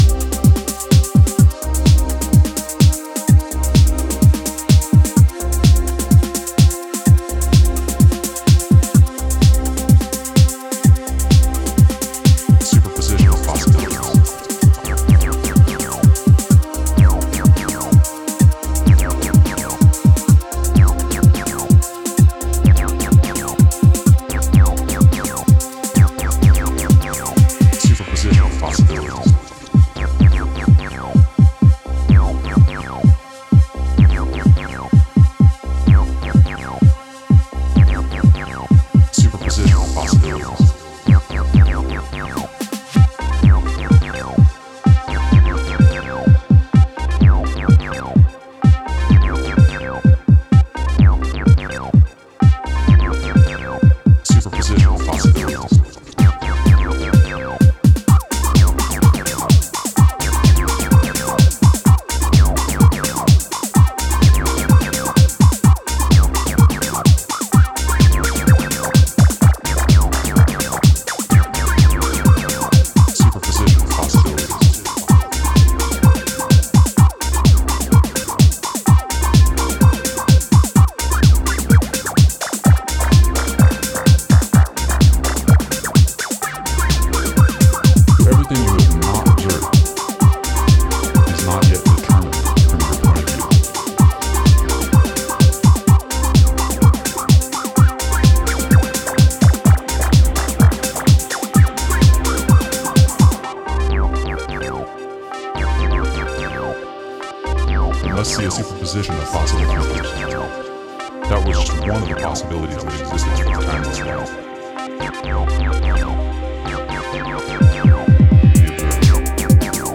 a masterclass in acid-drenched intensity